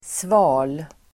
Uttal: [sva:l]